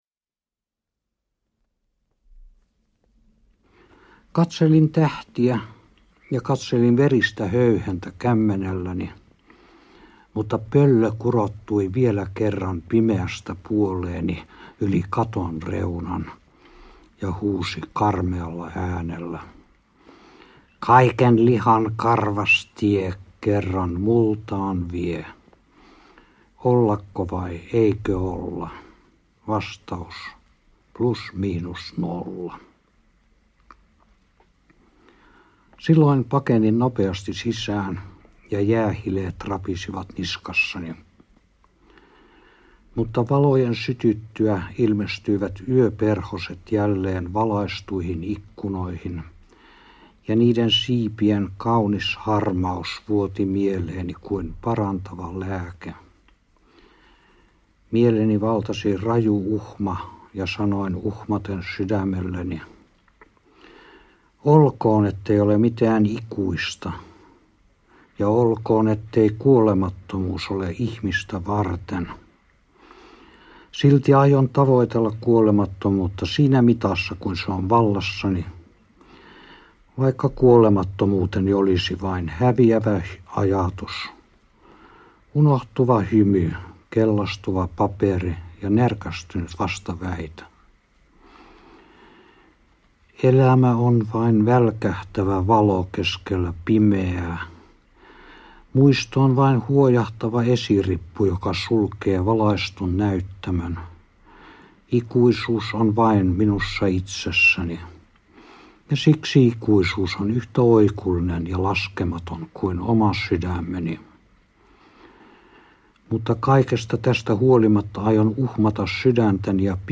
Vakavaa ja leikillistä – Ljudbok – Laddas ner
Waltarin itsensä lisäksi tekstejä lukevat Tauno Palo, Kalle Holmberg ja Veikko Sinisalo.
Uppläsare: Mika Waltari, Veikko Sinisalo, Kalle Holmberg, Tauno Palo